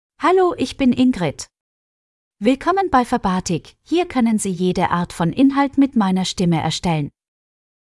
FemaleGerman (Austria)
Voice sample
Female
German (Austria)
Ingrid delivers clear pronunciation with authentic Austria German intonation, making your content sound professionally produced.